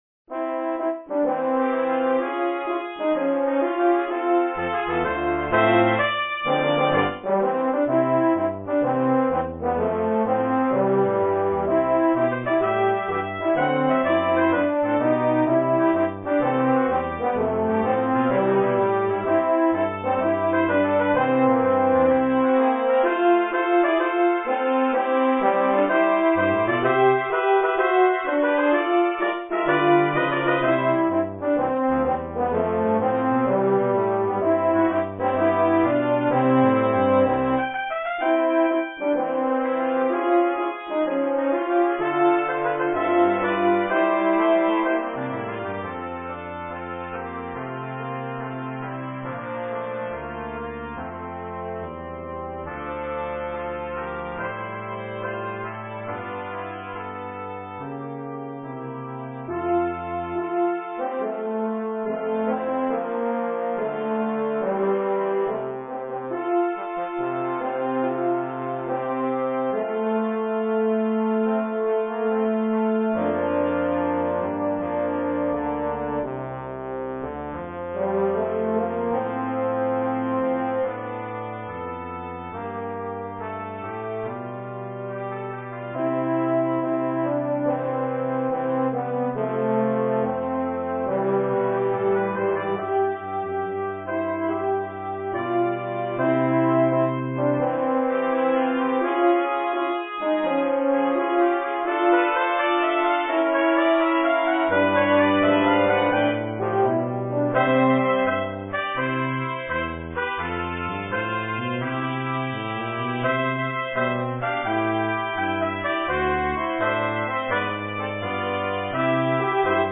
Voicing: Brass Sextet